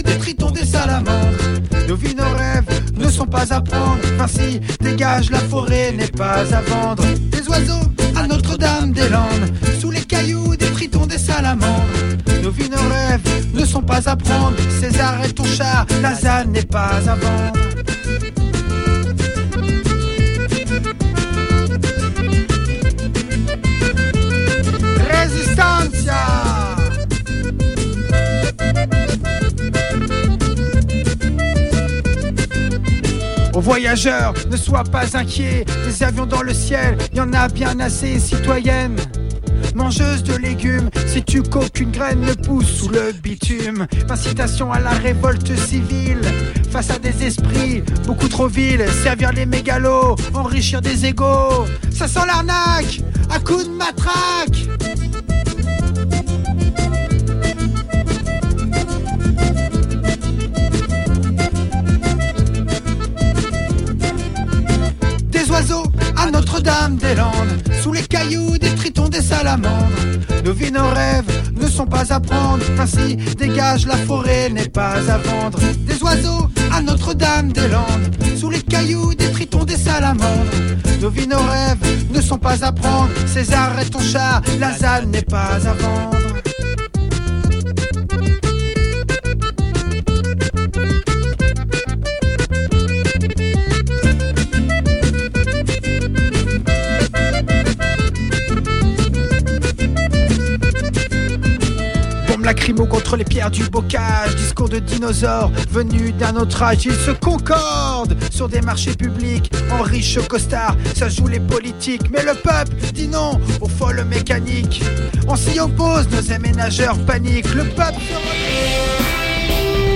L'enregistrement démarre 1 mn avant l'heure prévue du début de l'émission
Dans la deuxième partie, nous recevons Gilbert Achcar, chercheur marxiste, écrivain et professeur de sciences politiques et de relations internationales.